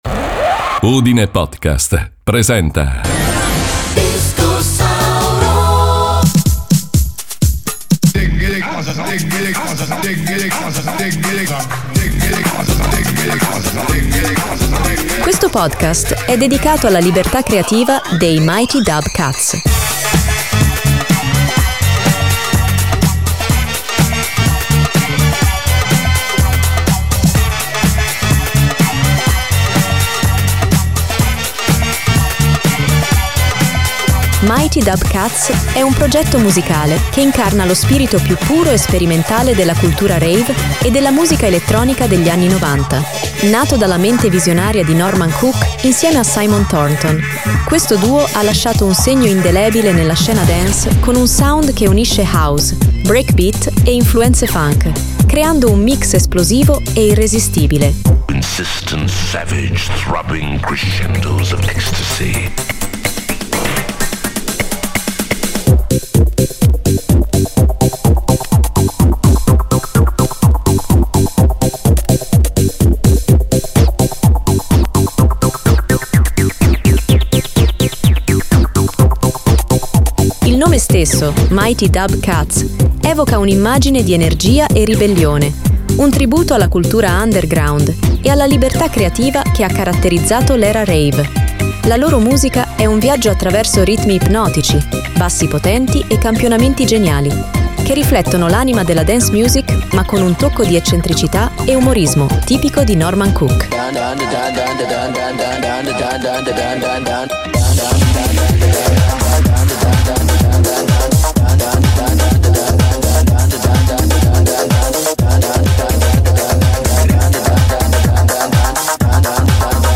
Questo duo ha lasciato un segno indelebile nella scena dance con un sound che unisce house, breakbeat e influenze funk, creando un mix esplosivo e irresistibile.
La loro musica è un viaggio attraverso ritmi ipnotici, bassi potenti e campionamenti geniali, che riflettono l’anima della dance music ma con un tocco di eccentricità e umorismo tipico di Norman Cook.